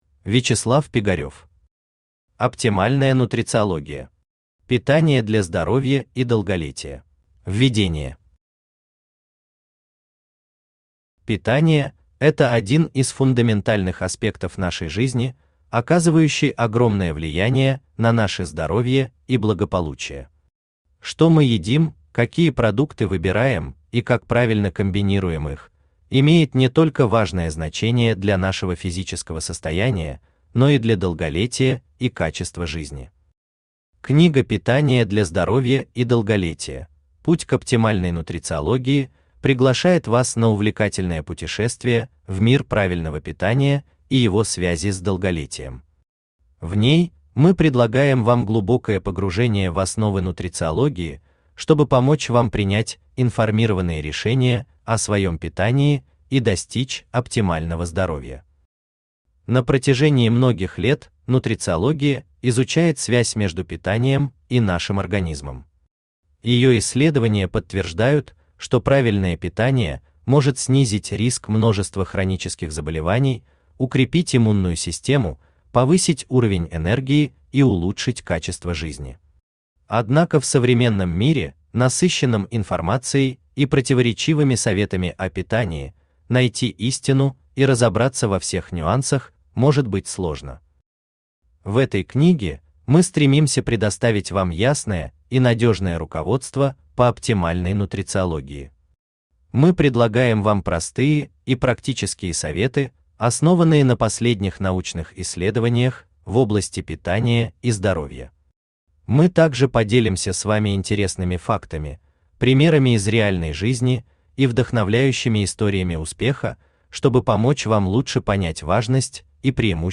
Питание для здоровья и долголетия Автор Вячеслав Пигарев Читает аудиокнигу Авточтец ЛитРес.